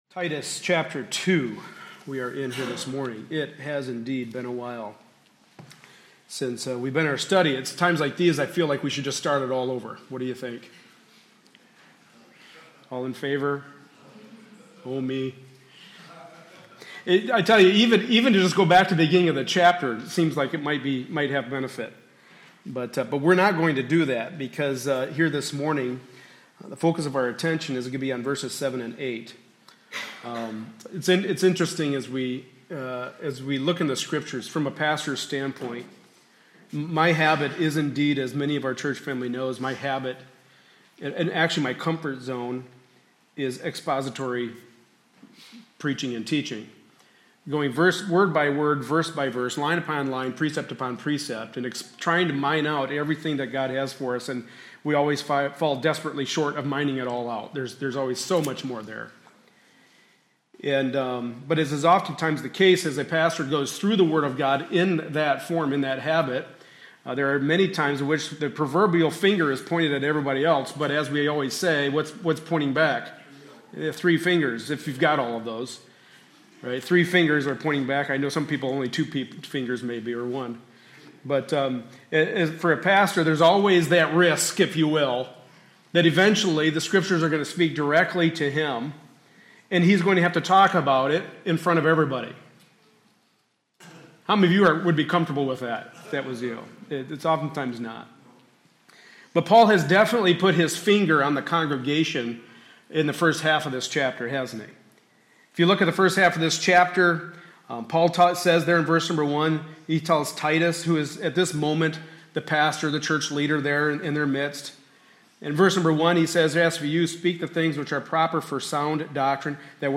Passage: Titus 2:7-8 Service Type: Sunday Morning Service